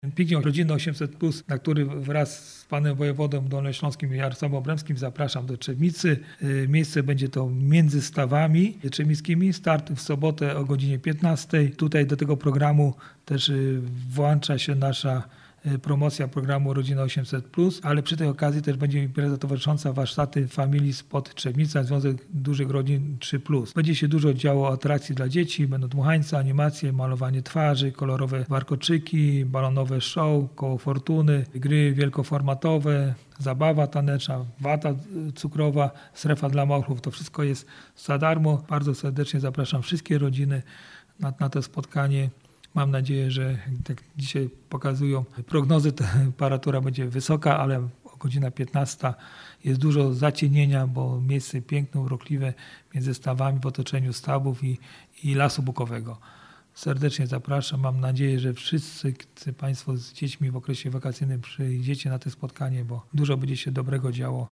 Mówi Marek Długozima, burmistrz Trzebnicy.